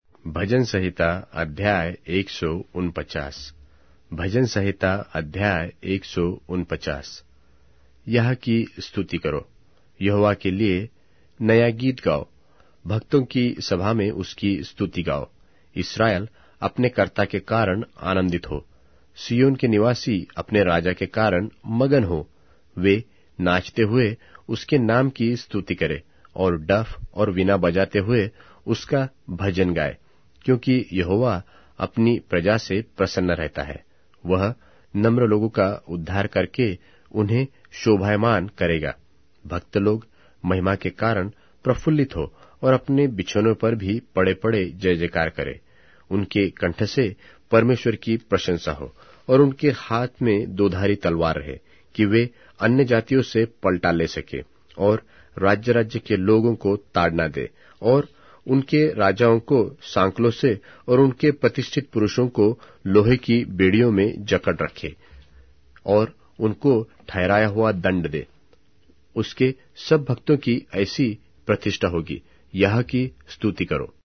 Hindi Audio Bible - Psalms 99 in Ervpa bible version